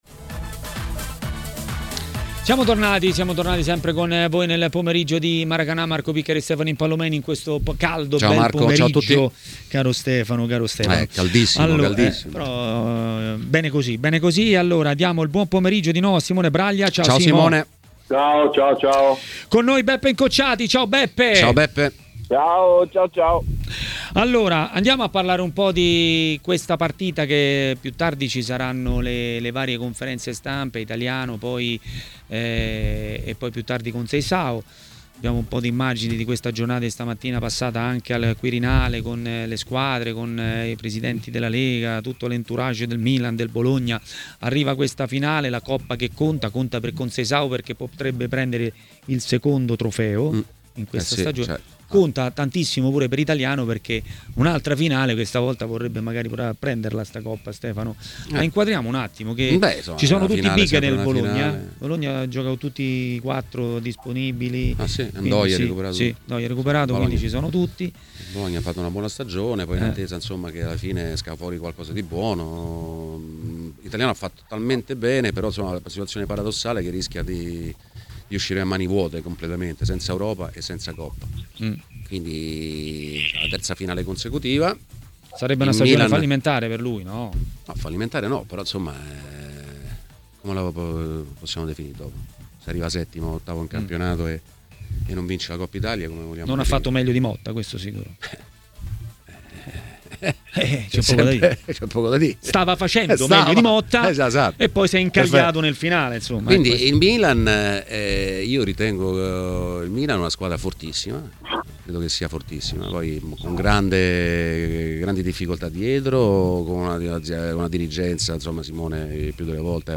Rispone così ai microfoni di Tmw Radio l'ex attaccante del Napoli Beppe Incocciati : " Adesso è il momento in cui la preparazione è molto più psicologica.